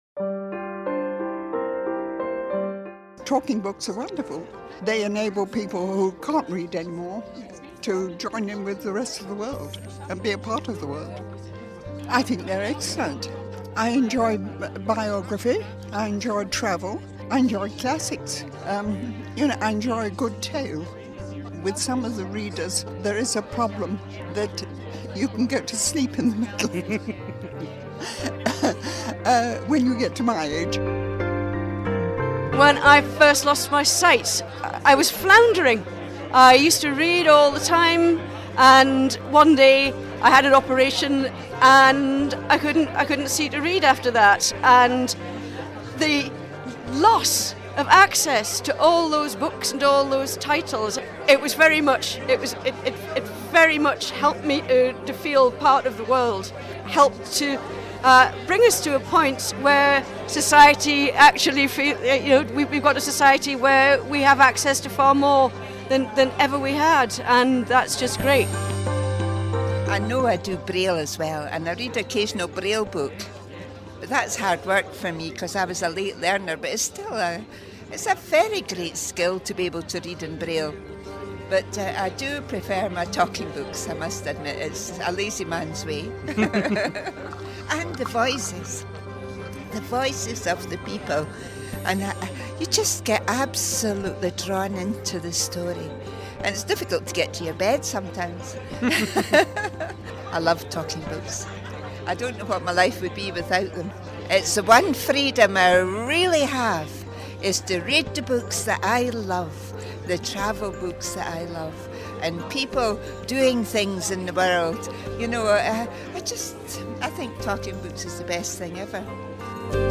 We're celebrating the fact that one year ago, the Talking Book service became a completely free. We hear from some listeners about what the service means to them.